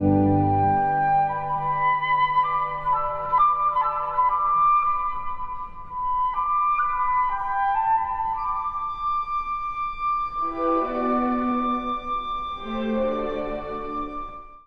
↑古い録音のため聴きづらいかもしれません！（以下同様）
そして雰囲気を一気に変えるのが、ト長調のフルートによる主題。
ロマン派らしい、息の長い旋律で歌い上げます。